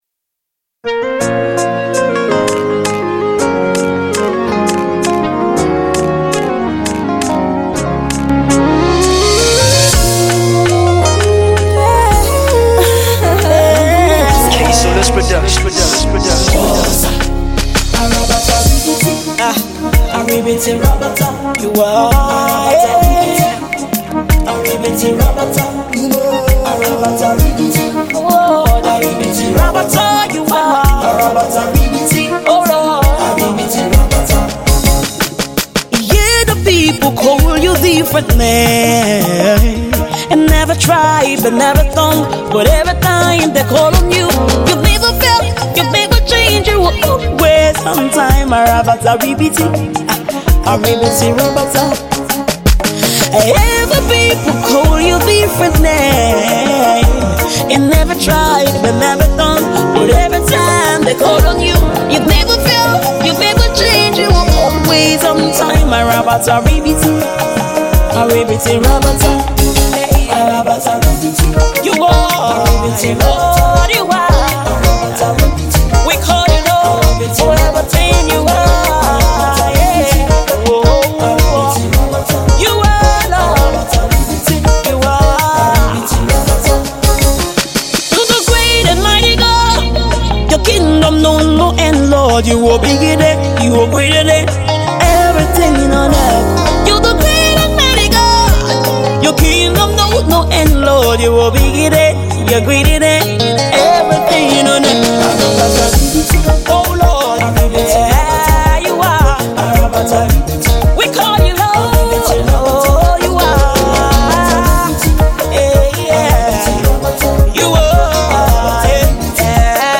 Gospel inspirational